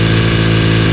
Engines